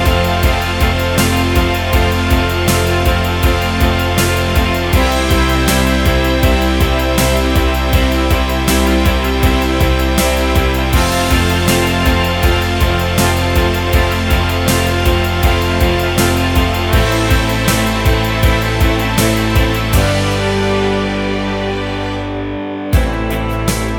no Backing Vocals Ska 3:39 Buy £1.50